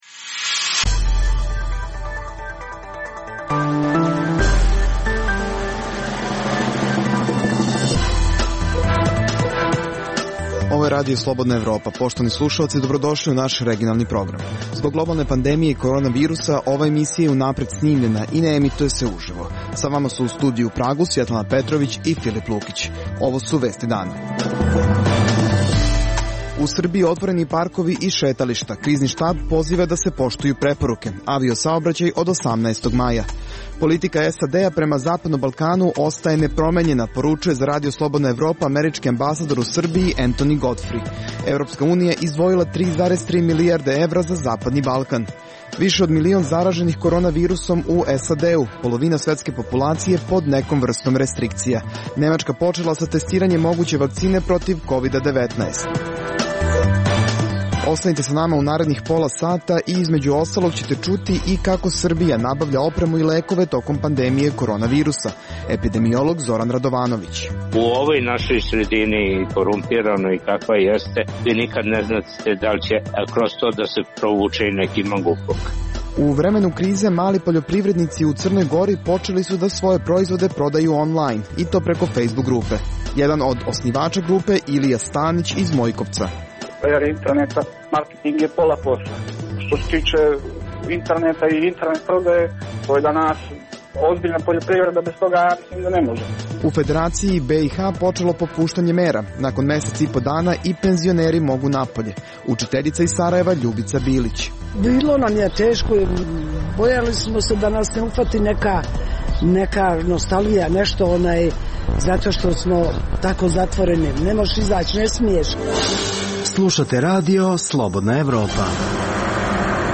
Zbog globalne pandemije korona virusa, ova emisija je unapred snimljena i ne emituje se uživo. U Srbiji otvoreni parkovi i šetališta.